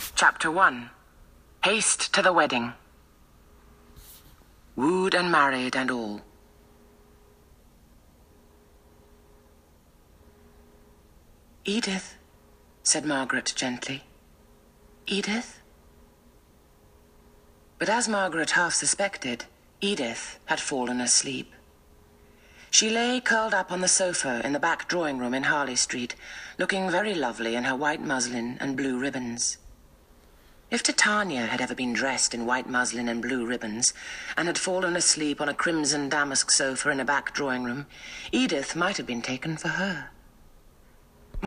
audiobooks
She altered her voice in subtle ways to capture differences in characters and was utterly convincing.